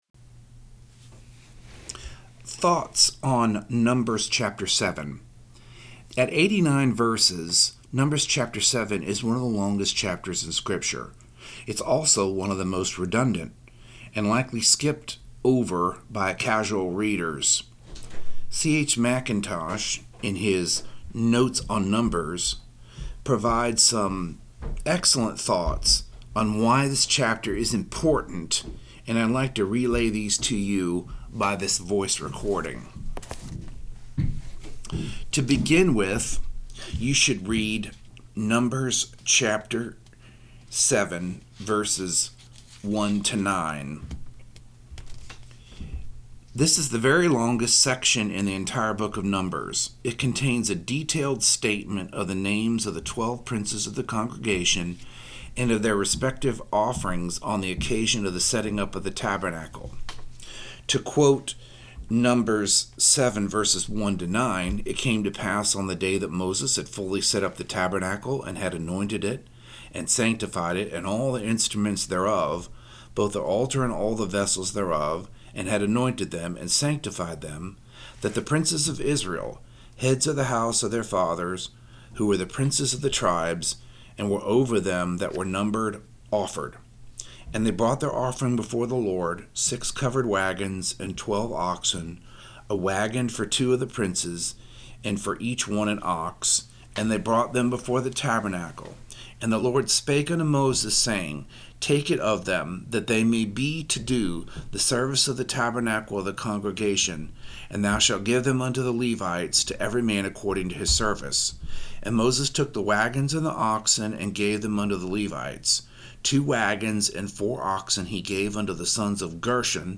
In the recording at the link below (mp4 format), I read C.H. Mackintosh’s comments on Num. 7 in his work Notes on Numbers.